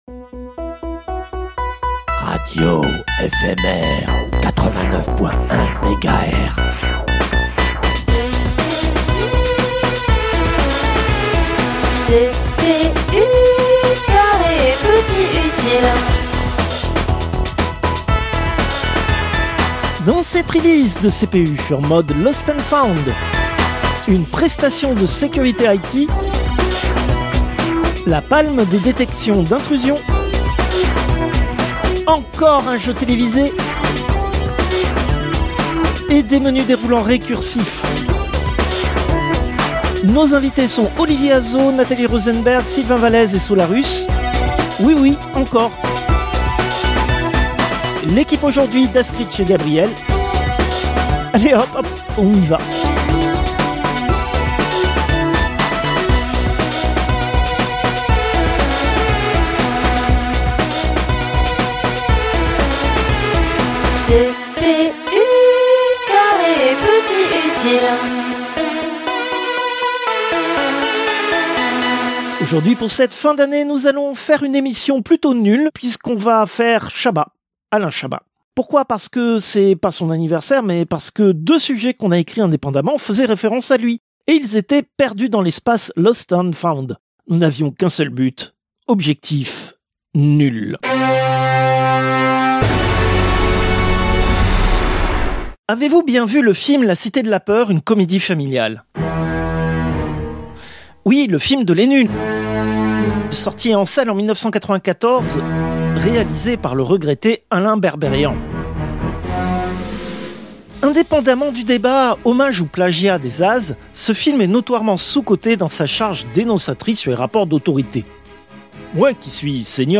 Fausse pub : Les réunions, c'est bien